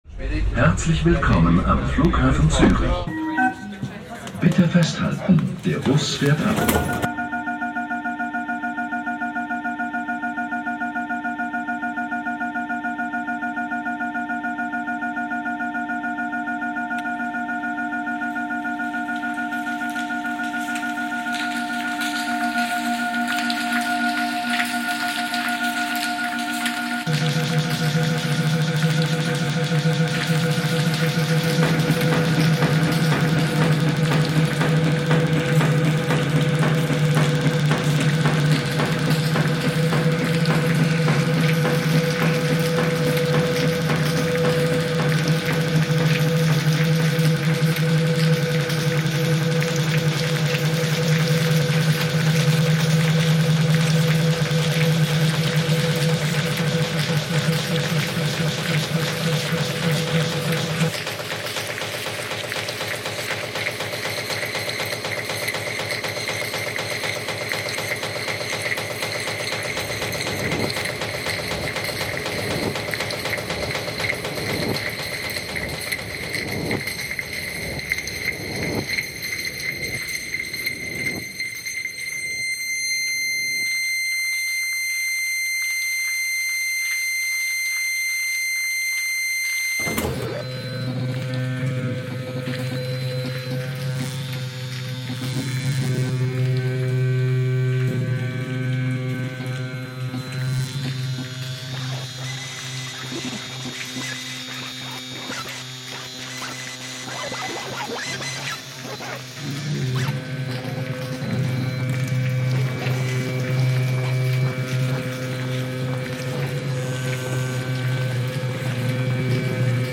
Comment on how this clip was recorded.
Zurich airport shuttle reimagined